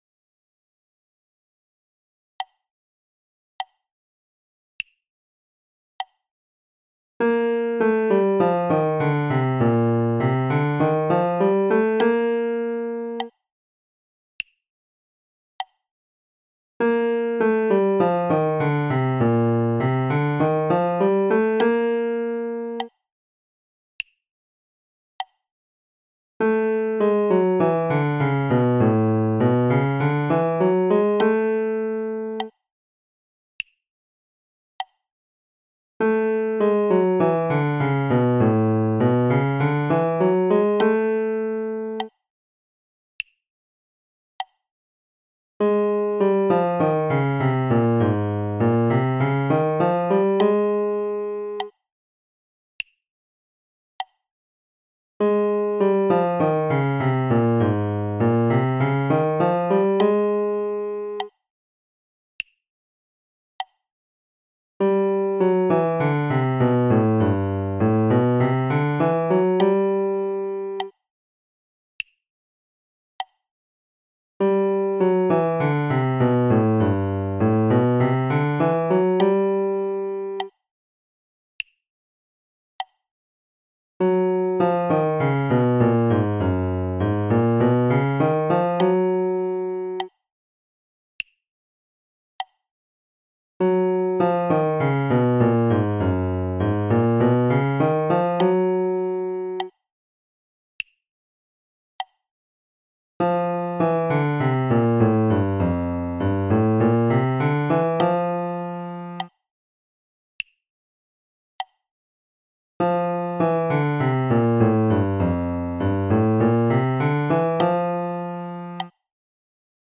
LOW REGISTER DEVELOPMENT
low-register-audio-2.mp3